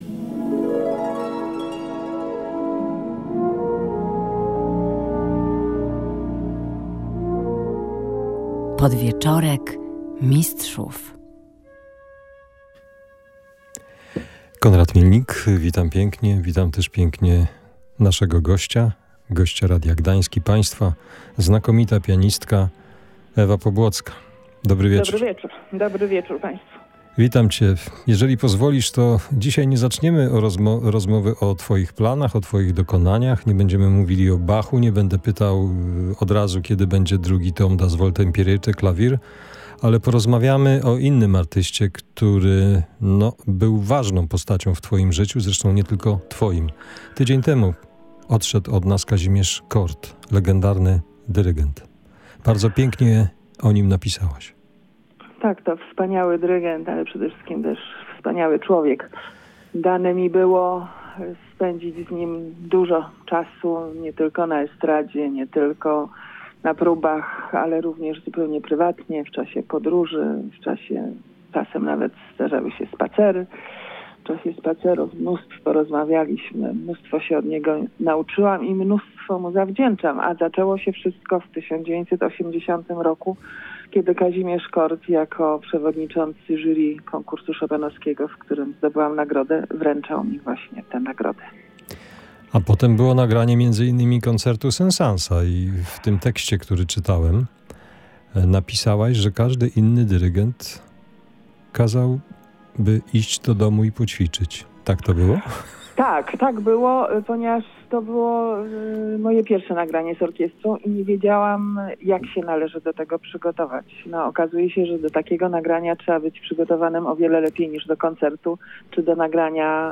Gościem „Podwieczorku Mistrzów” była Ewa Pobłocka – znakomita pianistka, a także autorka książki „Forte-piano” i następnej pozycji, która ukaże się niebawem.